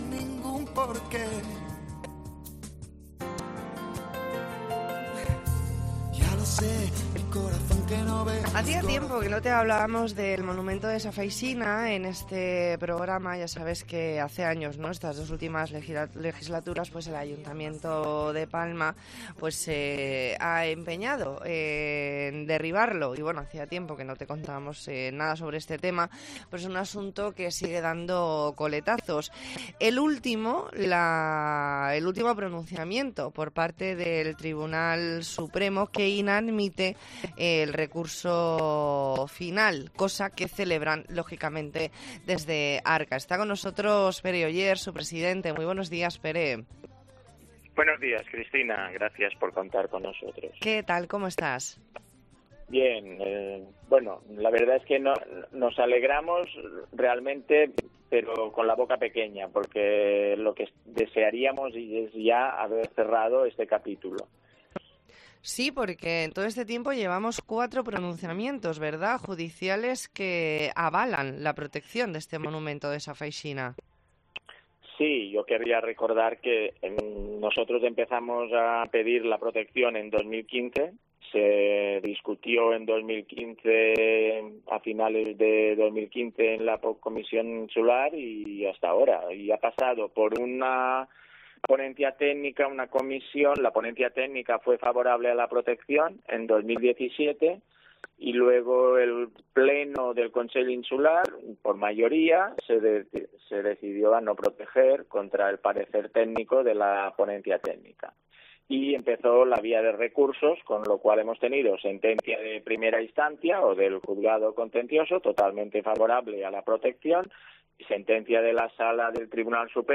E ntrevista en La Mañana en COPE Más Mallorca, lunes 10 de octubre de 2022.